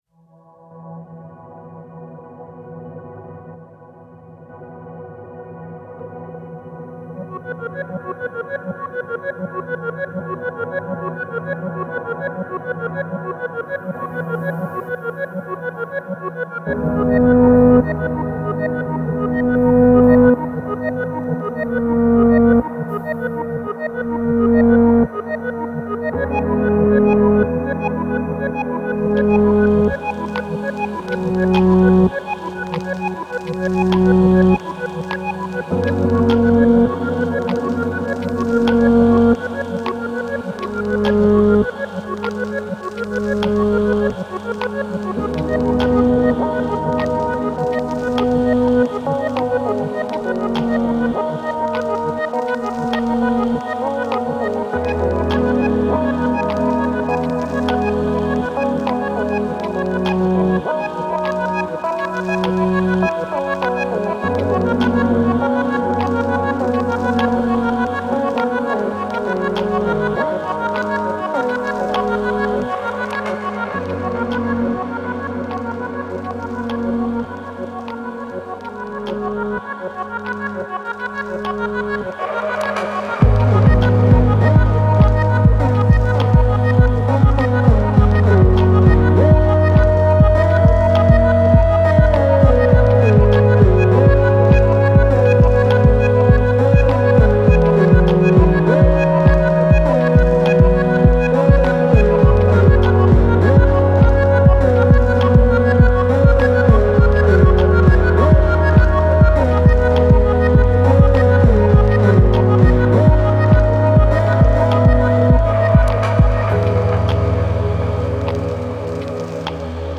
Granular synths, textural crackles and glitchy pulses.